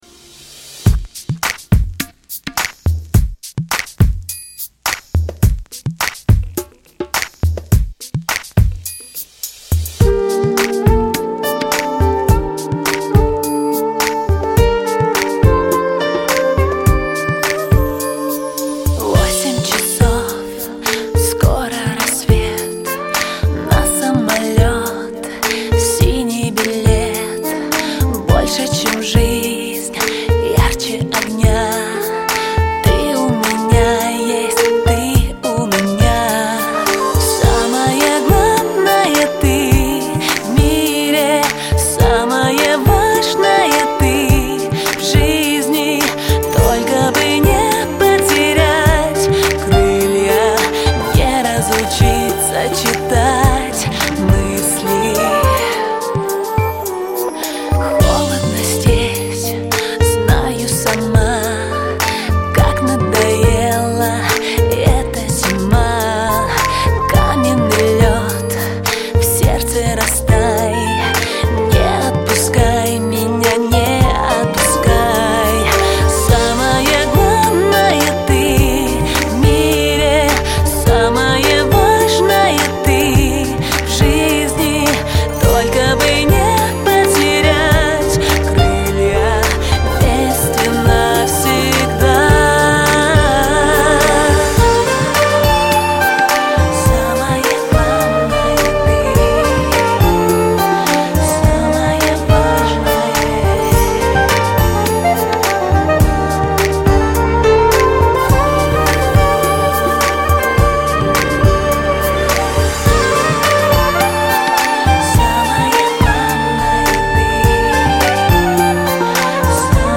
Категорія: Спокійні